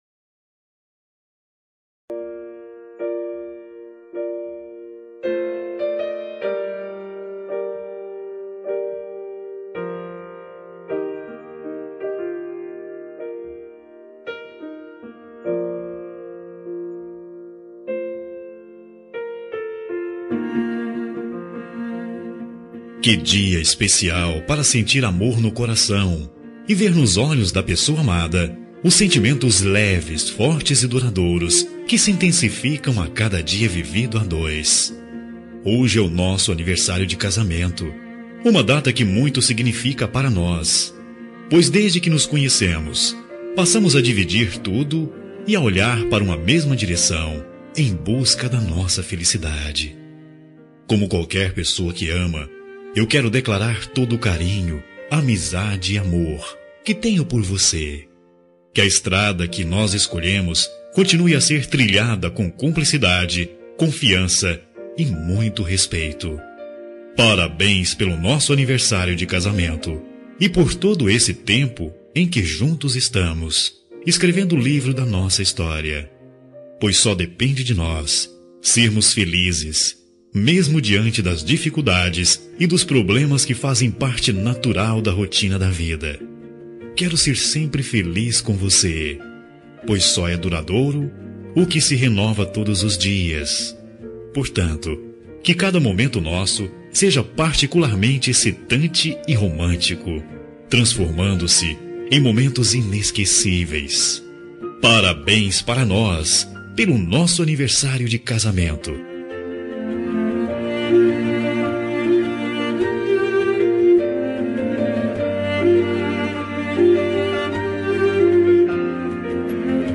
Telemensagem de Aniversário de Casamento Romântico – Voz Masculina – Cód: 4236